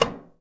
metal_tin_impacts_soft_03.wav